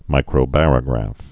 (mīkrō-bărə-grăf)